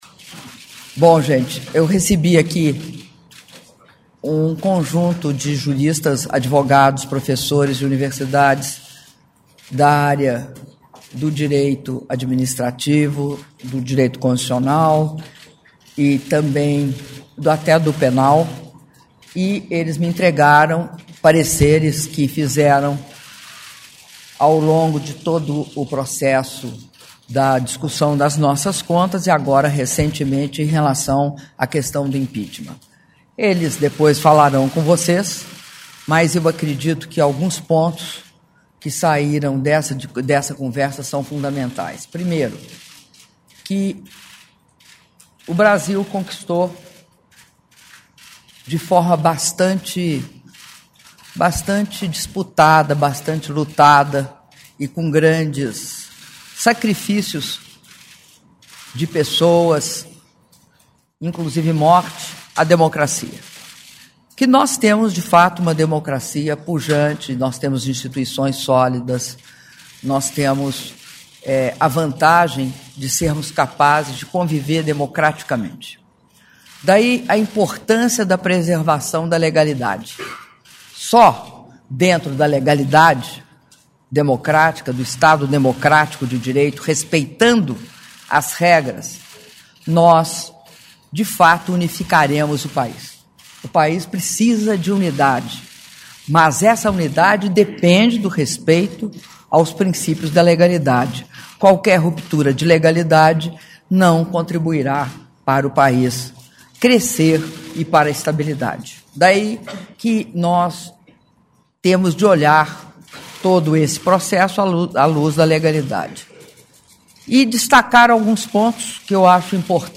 Áudio da entrevista coletiva concedida pela Presidenta da República, Dilma Rousseff, após reunião com juristas - Brasília/DF (08min02s)